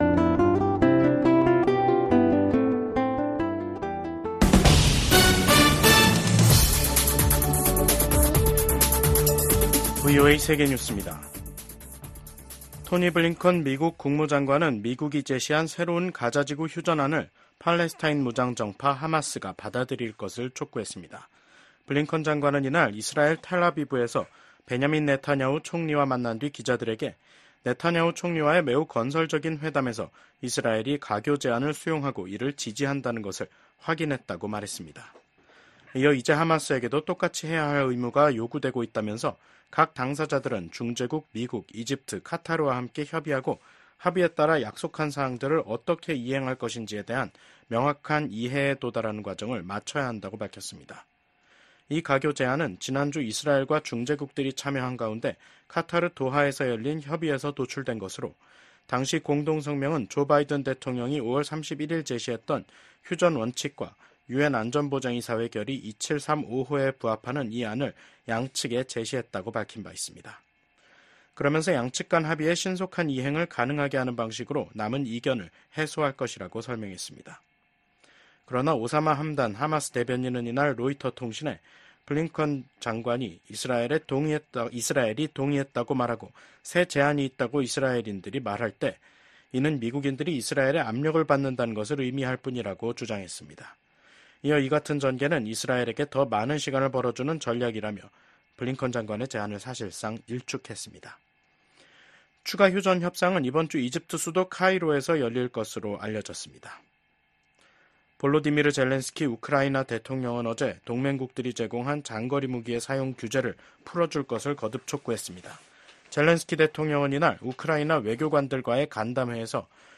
VOA 한국어 간판 뉴스 프로그램 '뉴스 투데이', 2024년 8월 20일 3부 방송입니다. 11월 대선을 앞두고 미국 민주당이 북한의 위협에 맞선 한국에 대해 변함없는 지지를 재확인하는 새 정강을 발표했습니다. 지난해 8월 캠프 데이비드에서 열린 미한일 3국 정상회의는 동북아에서 3국의 안보 협력을 한 단계 끌어올린 대표적 외교 성과라고 미 전문가들이 평가했습니다. 북한 군 병사 한 명이 20일 새벽 동부전선 군사분계선을 넘어 한국으로 망명했습니다.